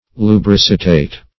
Lubricitate \Lu*bric"i*tate\, v. i.